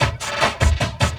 45LOOP SD1-R.wav